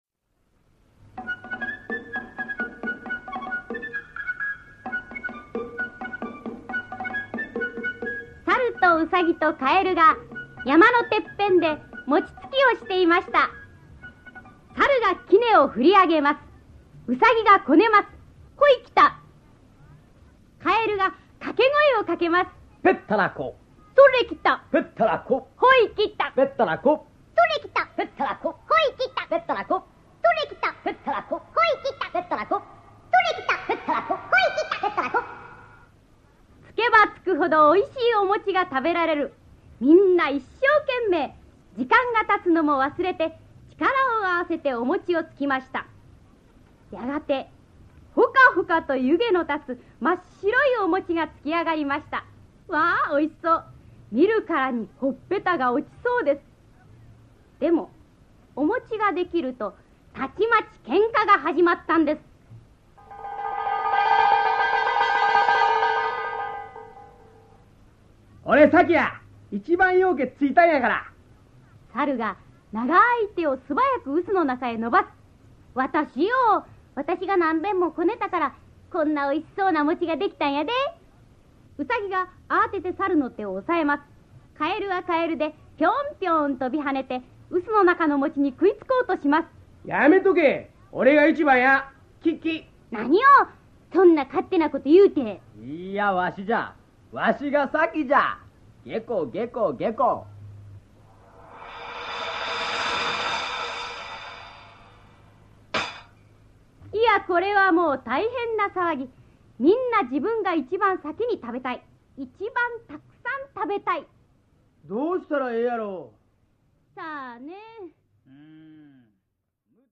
[オーディオブック] もちを食べたかえる